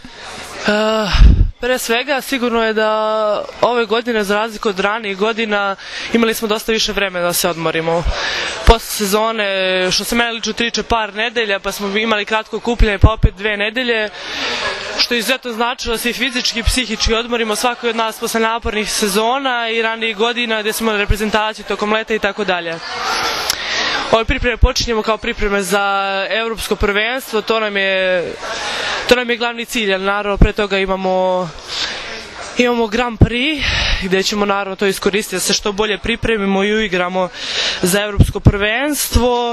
IZJAVA TIJANE MALEŠEVIĆ